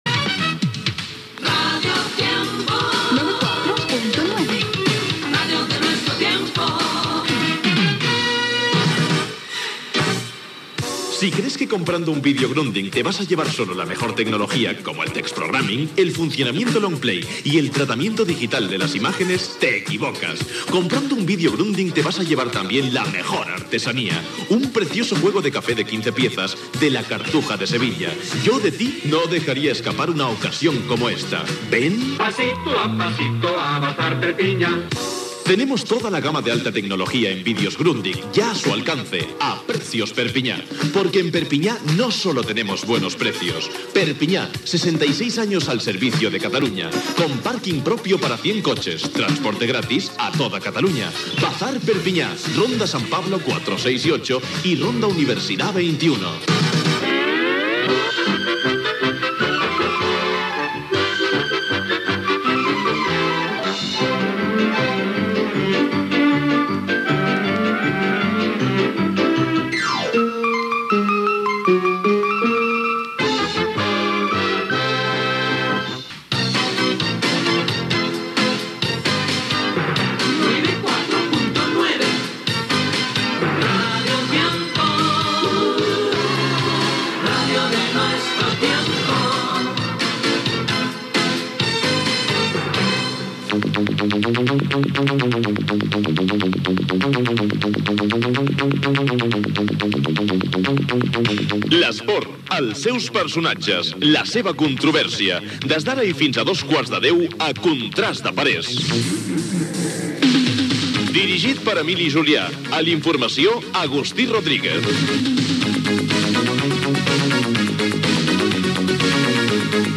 Indicatiu de l'emissora, publicitat, indicatiu de l'emissora, careta del programa amb els noms de l'equip, salutació, informació de bàsquet
FM